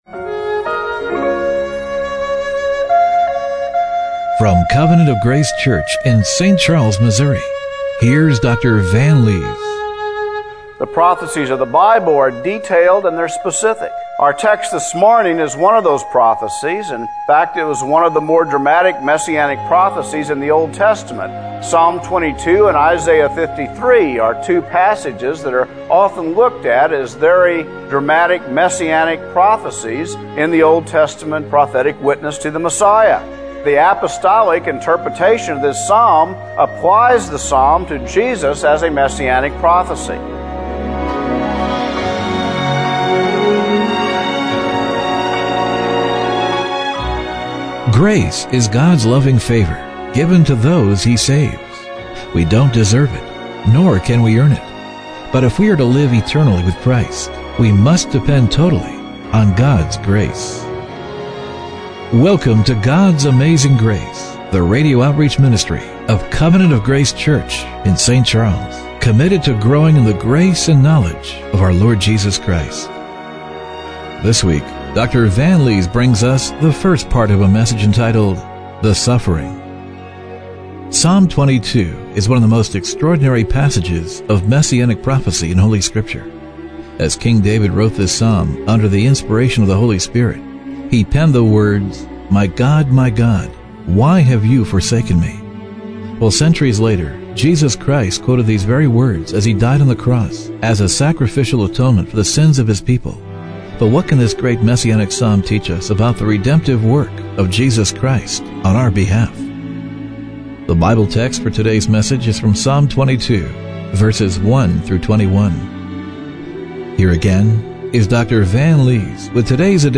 Psalm 22:1-21 Service Type: Radio Broadcast What can this great messianic psalm teach us about the redemptive work of Jesus on our behalf?